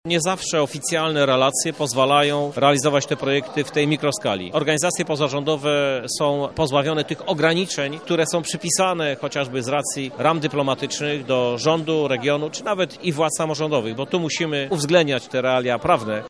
– mówi marszałek województwa Sławomir Sosnowski.